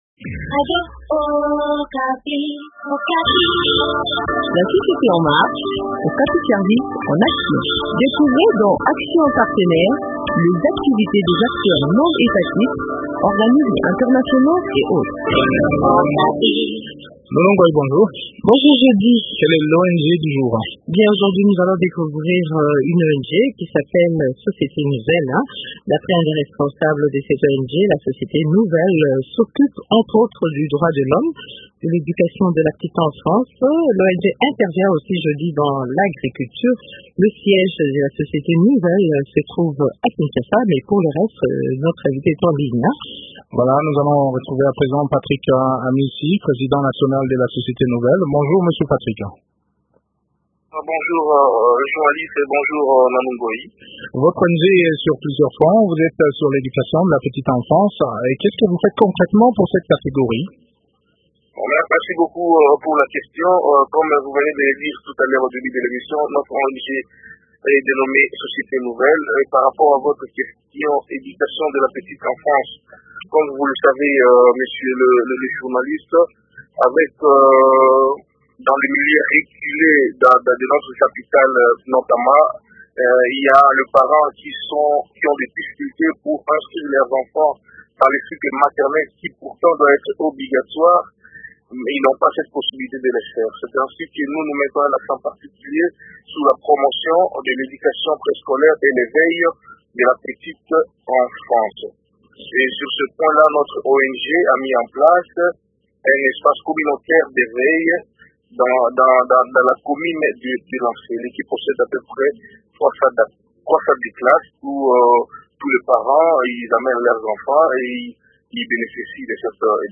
Découvrez les différentes activités cette ONG dans cet entretien